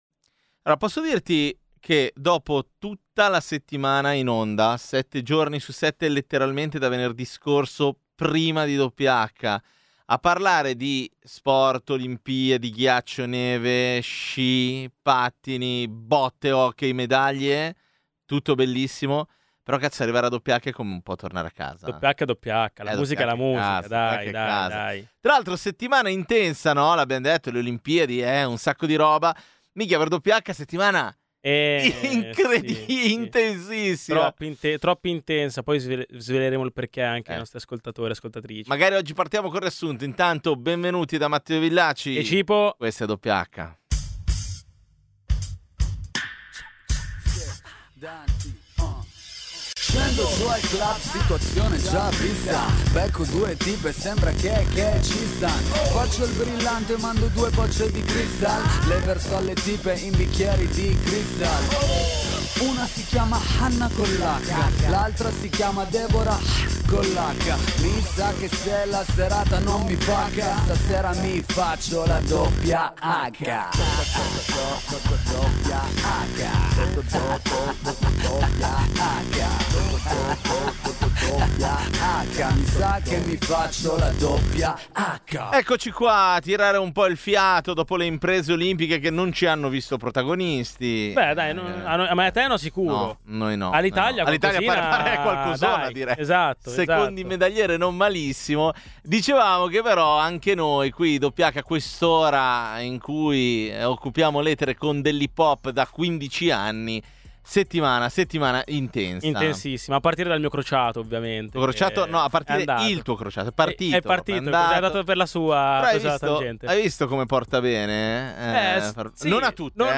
Dal 2011 è la trasmissione dedicata all’hip-hop di Radio Popolare.